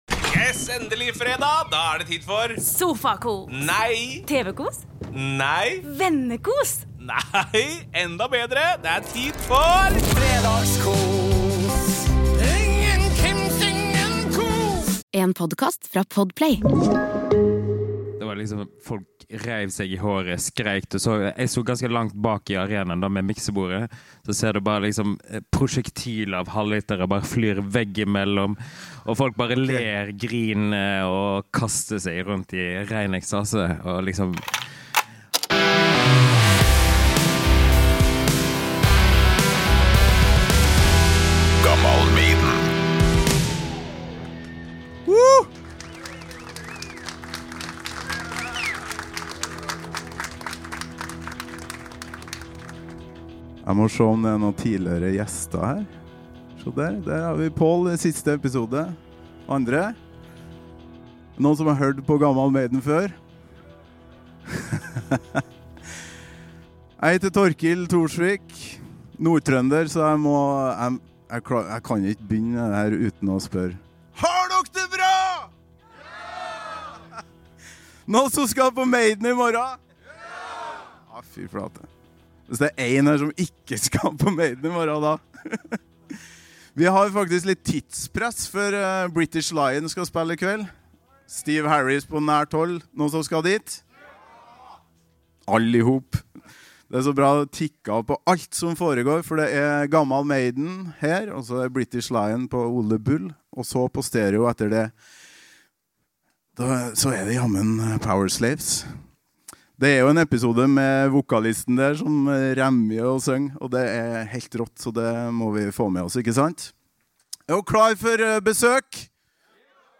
Live fra Kulturhuset i Bergen